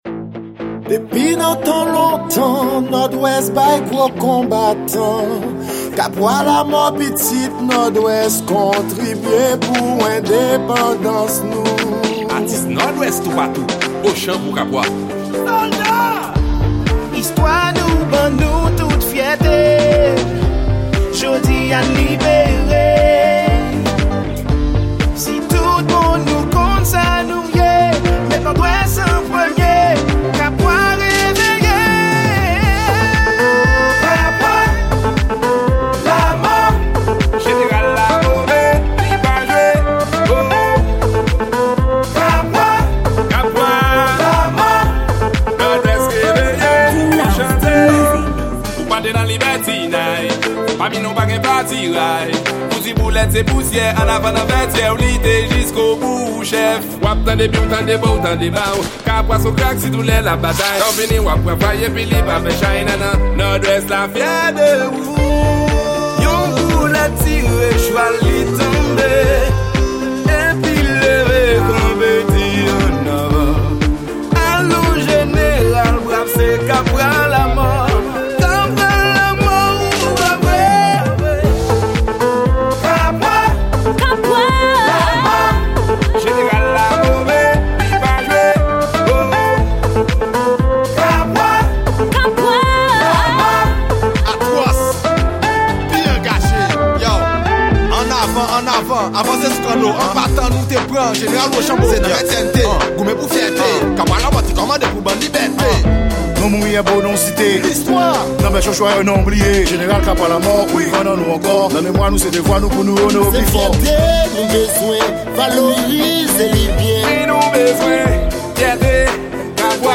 Genre: World